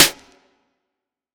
SouthSide Snare Roll Pattern (46).wav